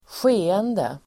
Ladda ner uttalet
Uttal: [²sj'e:ende]